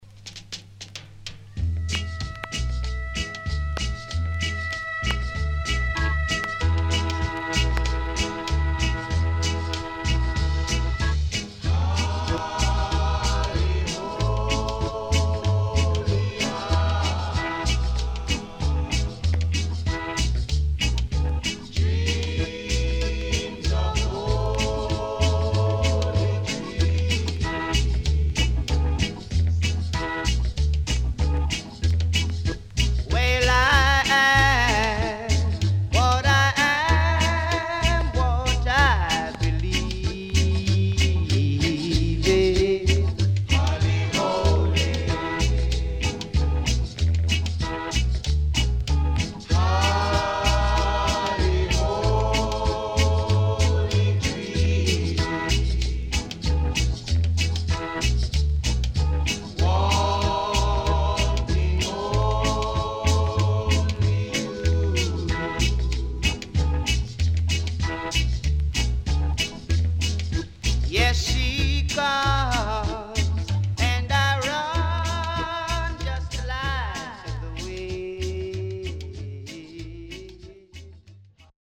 Nice Vocal.W-Side Good
SIDE B:うすいこまかい傷ありますがノイズあまり目立ちません。